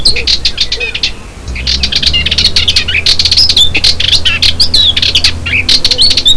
L'Occhiocotto maschio (nella foto) canta di solito nascosto nel folto di un cespuglio o di una fitta siepe. Il suo aspro cinguettio è abbastanza monotono e si ripete con ritornelli di 3/6 secondi : per ascoltarlo fai click
Questo canto è uno dei tipici "suoni" del fitto sottobosco della macchia mediterranea.